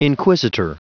Prononciation du mot inquisitor en anglais (fichier audio)
Prononciation du mot : inquisitor